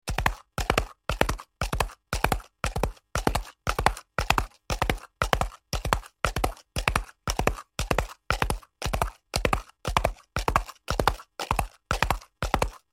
دانلود آهنگ اسب 9 از افکت صوتی انسان و موجودات زنده
جلوه های صوتی
دانلود صدای اسب 9 از ساعد نیوز با لینک مستقیم و کیفیت بالا